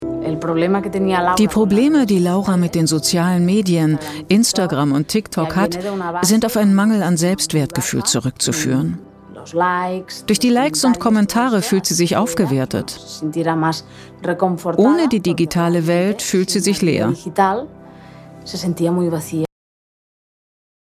sehr variabel
Mittel minus (25-45)
Doku